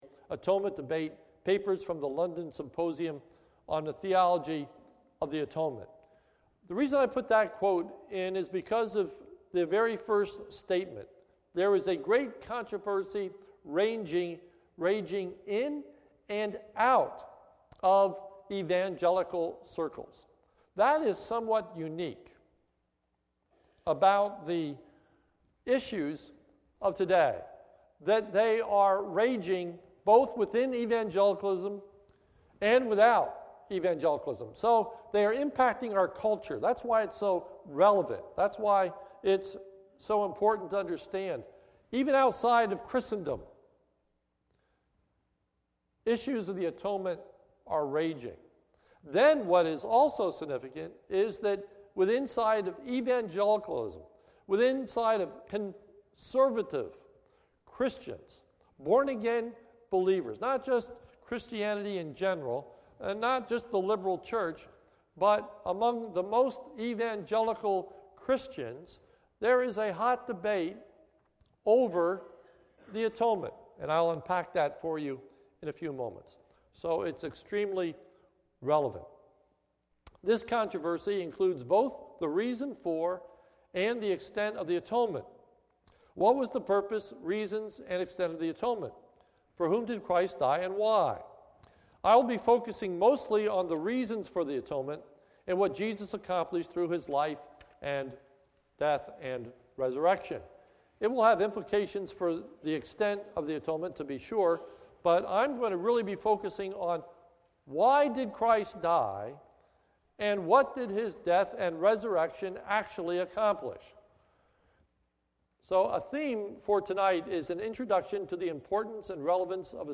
This is a sermon recorded at the Lebanon Bible Fellowship Church, in Lebanon, PA, on 3/23/2014 during the evening service, entitled “Why Study the Atonement?”